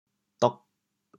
“笃”字用潮州话怎么说？